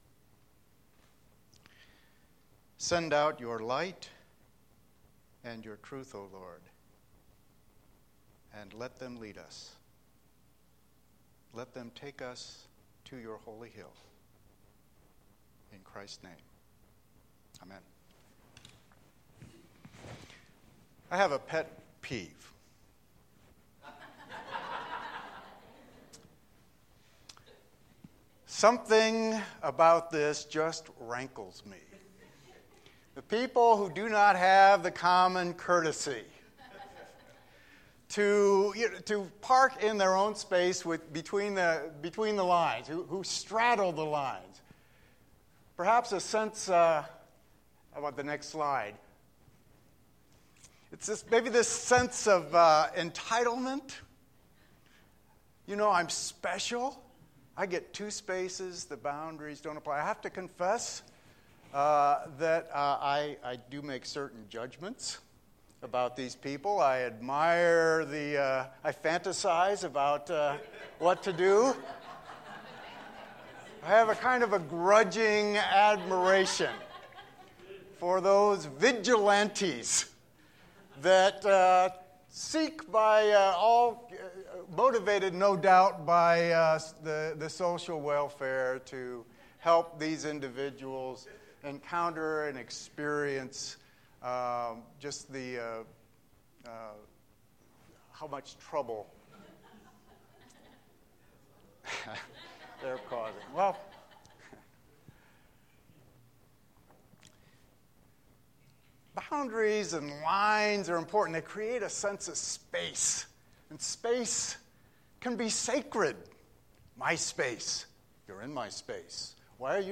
The message was recorded on Wednesday March 18, 2015 during our Seminary Chapel.